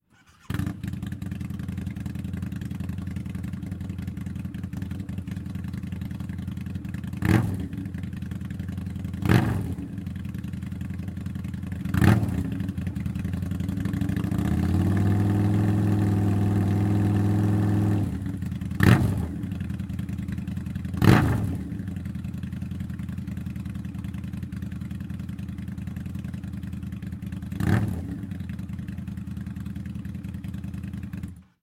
Vrod2into1.mp3